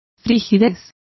Complete with pronunciation of the translation of frigidity.